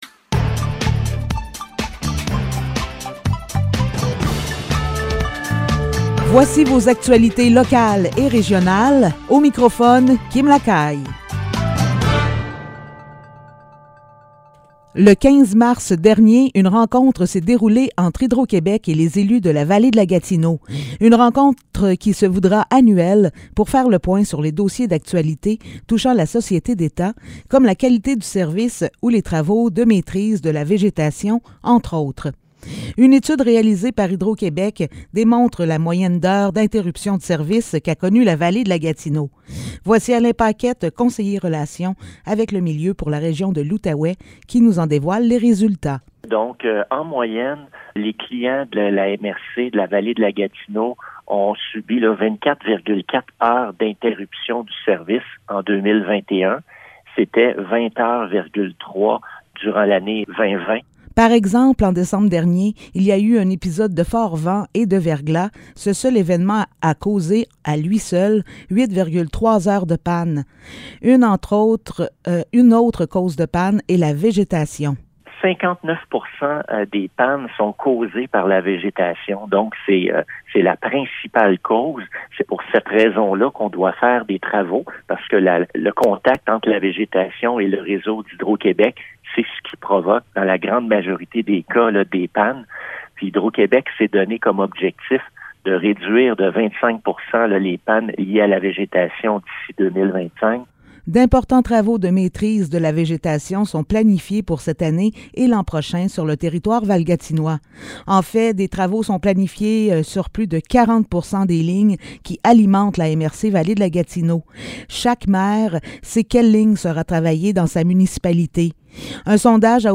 Nouvelles locales - 23 mars 2022 - 15 h